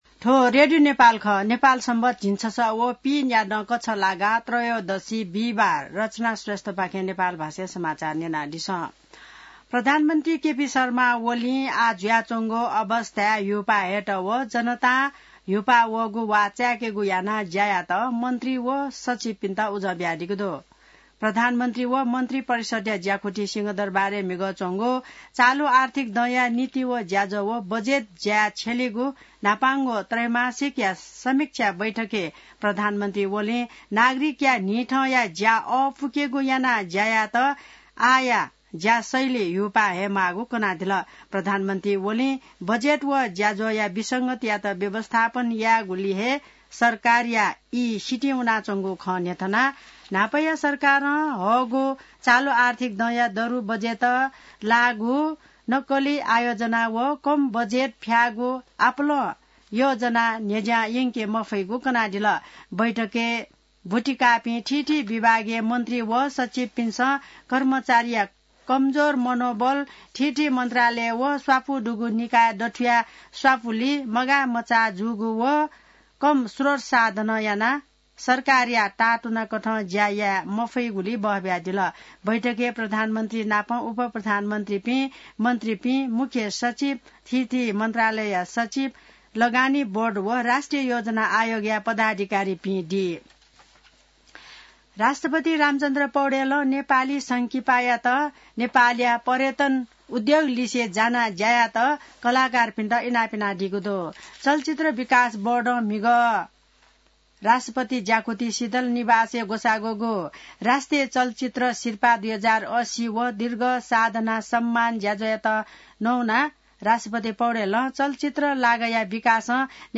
नेपाल भाषामा समाचार : १४ मंसिर , २०८१